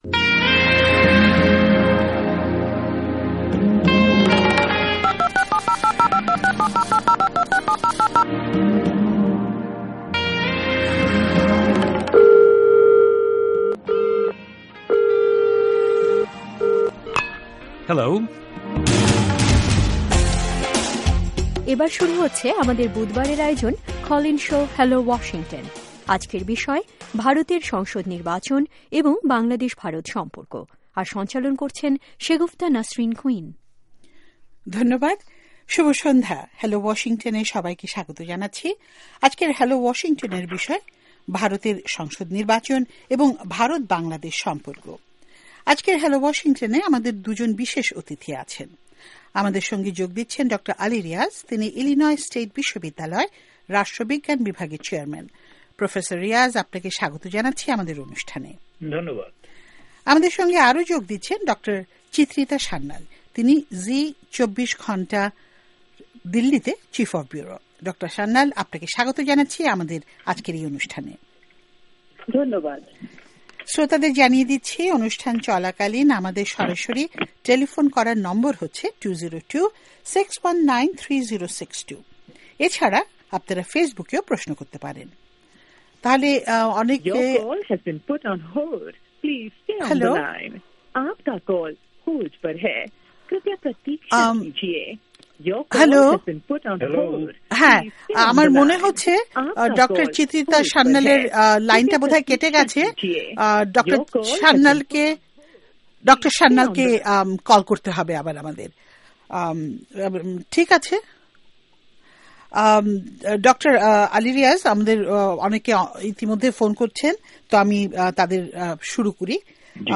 শ্রোতারা আজকের হ্যালো ওয়াশিংটনে ভারতের সংসদ নির্বাচন এবং ভারত-বাংলাদেশ সম্পর্ক মন্তব্য করেন ও বিভিন্ন প্রশ্ন করেন। বাংলাদেশ, ভারত সহ যুক্তরাষ্ট্রের পেনসিলভেনিয়া, নিউ ইয়র্ক এবং অন্যান্য স্থান থেকে লোকজন প্রশ্ন করেন।